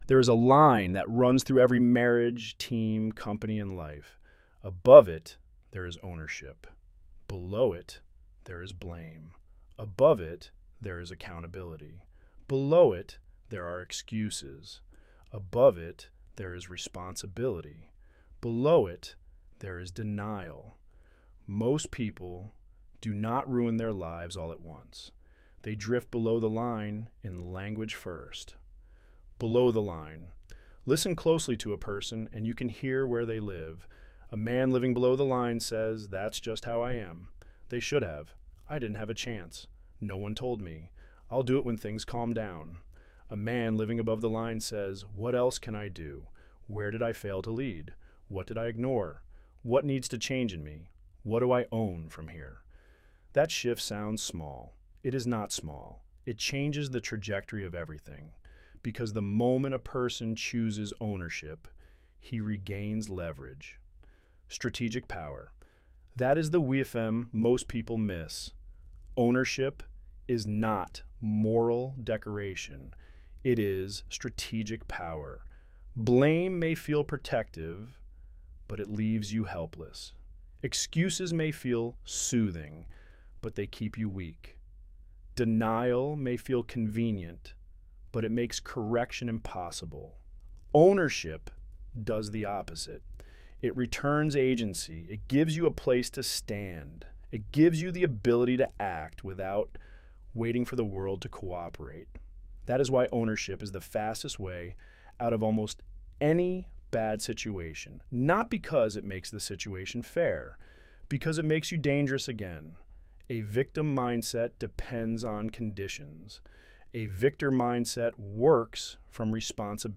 Narrated by the Author Download narration There is a line that runs through every marriage, team, company, and life.